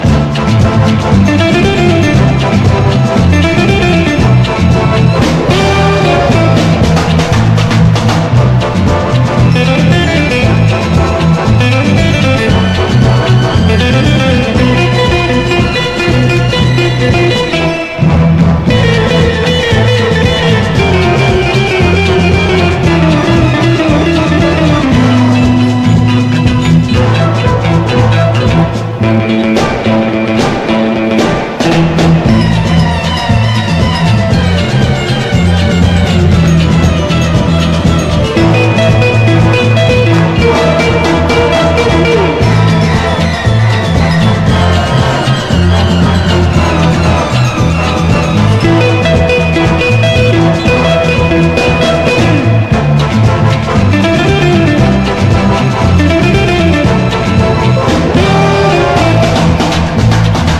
EASY LISTENING / EASY LISTENING / GUITAR / LOUNGE FUNK
数々のイージー・リスニング名作を残すギタリスト！ ギターの存在感が凡庸なイージー作とは一線を画します。
分厚いホーンとギターとの軽妙な掛け合いに悶絶必至！